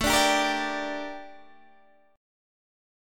Gm/A chord